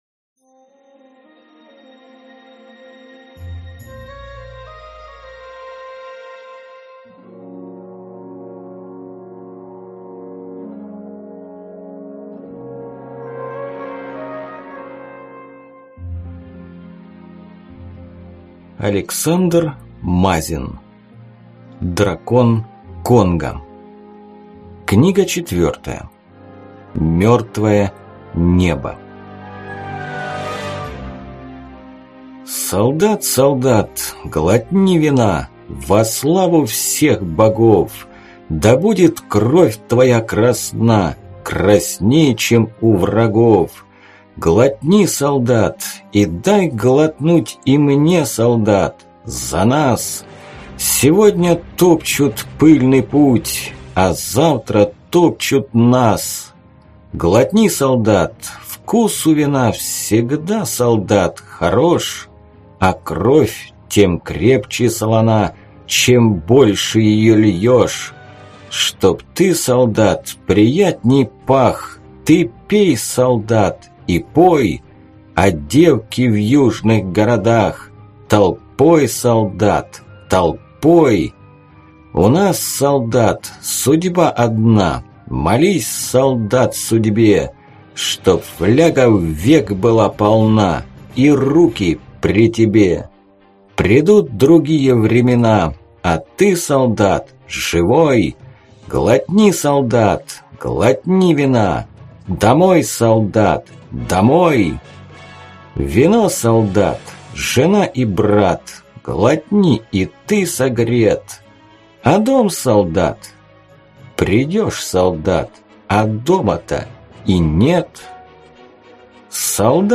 Аудиокнига Мертвое Небо | Библиотека аудиокниг